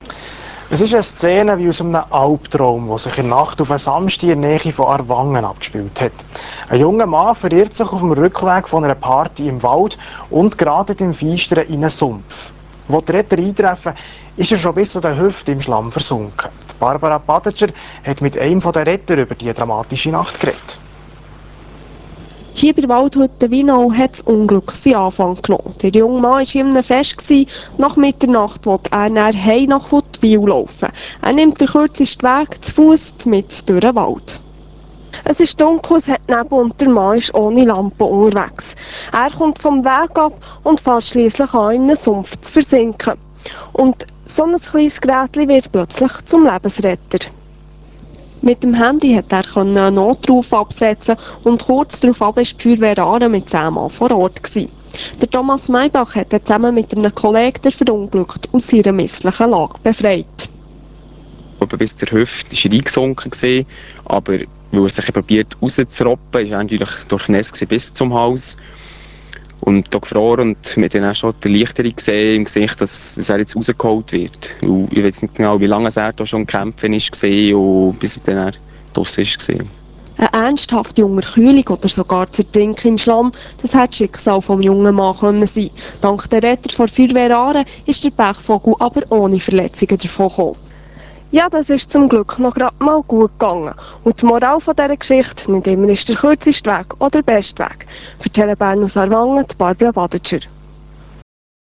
Fernsehbericht Tele Bärn  (Real Player) 1,8 MB